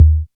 Bass (6).wav